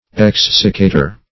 Search Result for " exsiccator" : The Collaborative International Dictionary of English v.0.48: Exsiccator \Ex"sic*ca`tor\, n. (Chem.) An apparatus for drying substances or preserving them from moisture; a desiccator; also, less frequently, an agent employed to absorb moisture, as calcium chloride, or concentrated sulphuric acid.